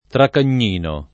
vai all'elenco alfabetico delle voci ingrandisci il carattere 100% rimpicciolisci il carattere stampa invia tramite posta elettronica codividi su Facebook Traccagnino [ trakkan’n’ & no ] pers. m. — maschera della commedia dell’arte